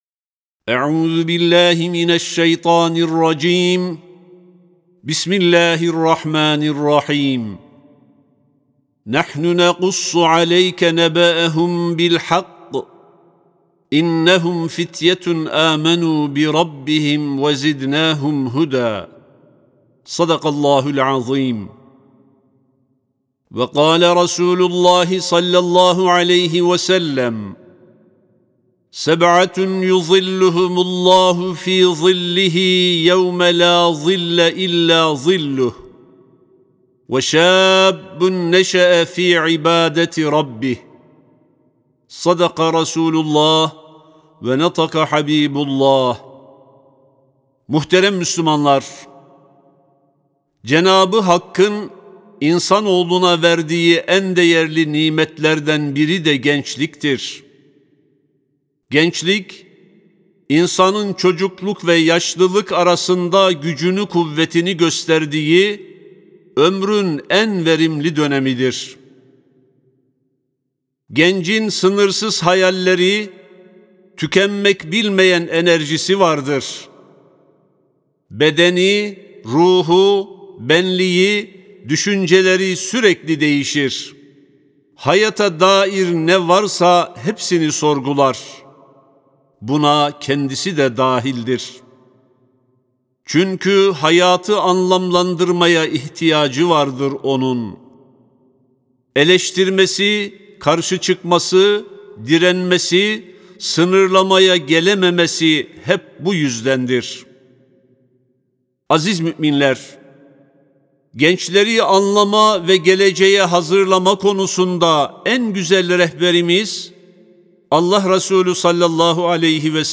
19.08.2022 Tarihli Cuma Hutbesi
Sesli Hutbe (Arşın Gölgesinde Korunmakla Müjdelenen Genç).mp3